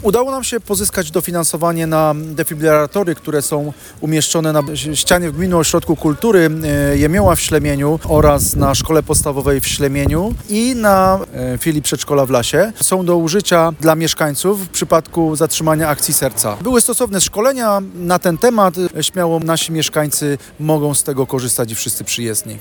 To niecodzienna inicjatywa, dlatego warto wiedzieć, że takie urządzenia są i pojawiła się możliwość odpowiedniego reagowania na zagrożenie, mówi wójt Ślemienia Jarosław Krzak.